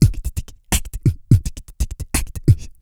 ELLISHUFFLE.wav